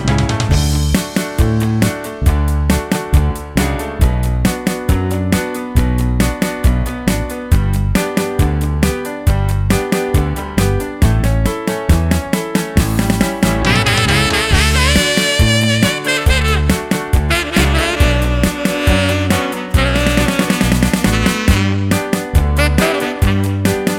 no Backing Vocals Soundtracks 2:20 Buy £1.50